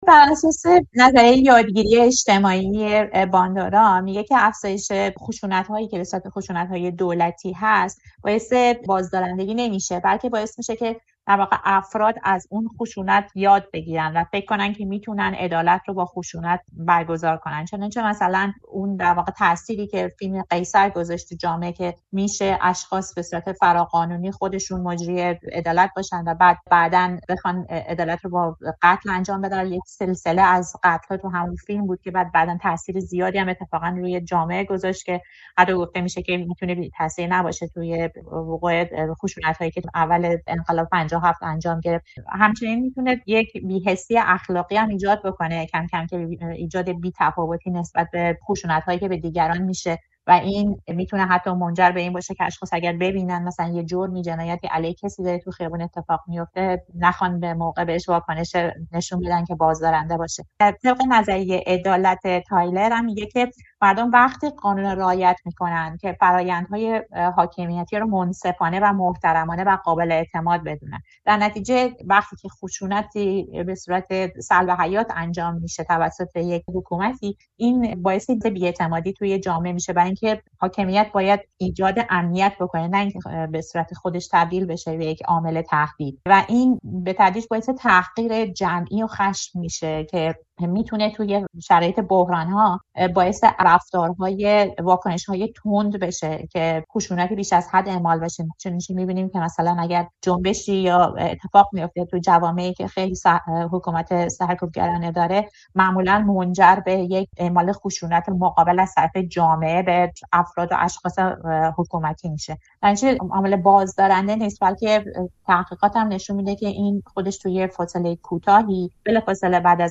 گفت‌وگو کرده‌ایم